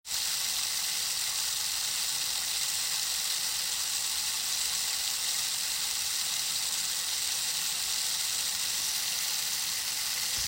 Pau de Chuva Contínuo
Produzido em material ecologicamente correto e revestido com cortiça, cada pau de chuva emite um ruído branco natural, criando uma experiência sonora profunda e calmante. Disponível em três tamanhos – 30 cm (duração aproximada de 2 minutos), 60 cm (5 minutos) e 84 cm (10 minutos) –, este instrumento proporciona um som alto e envolvente, ideal para práticas de meditação, relaxamento e para criar ambientes de paz e harmonia.